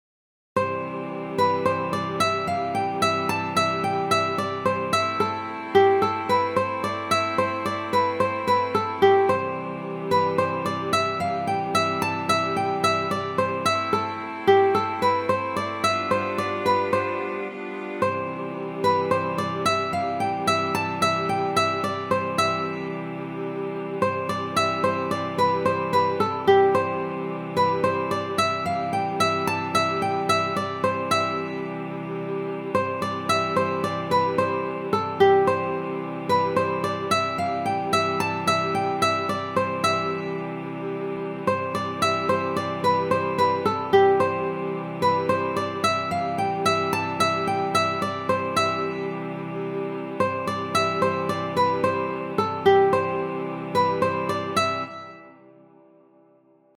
Mélodies